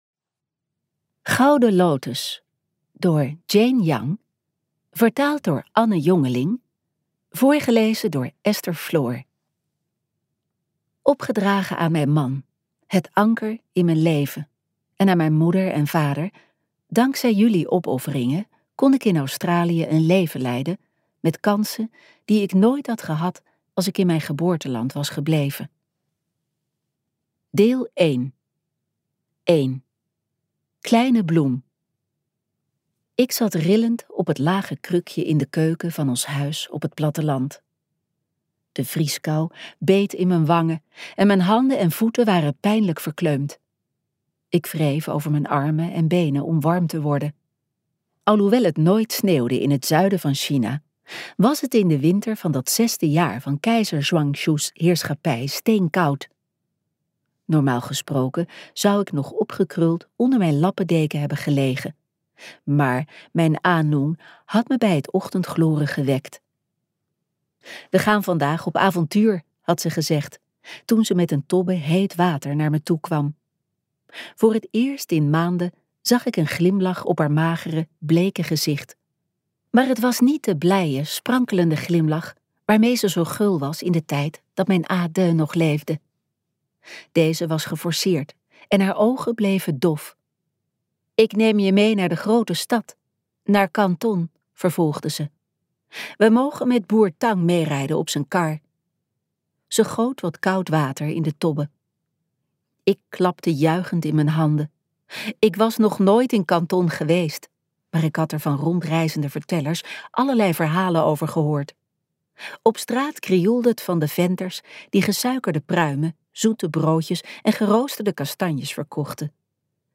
Ambo|Anthos uitgevers - Gouden lotus luisterboek